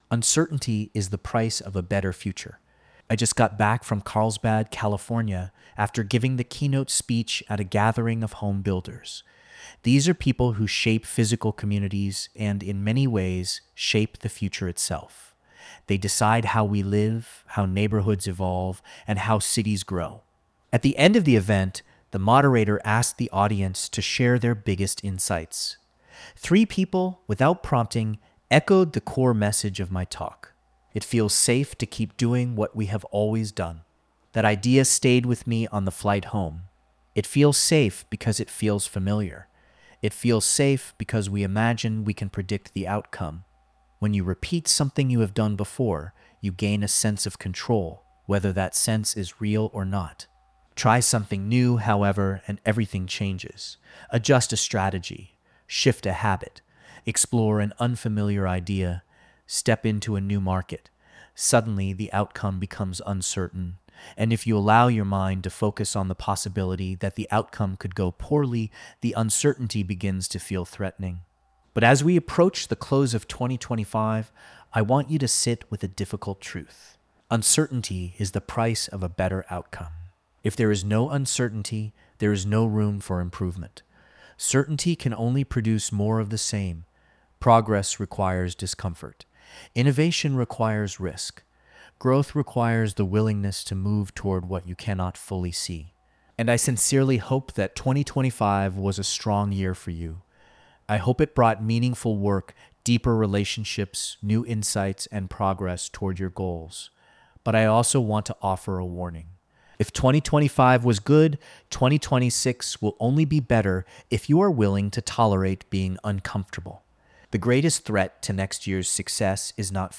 This audio was recorded by AI: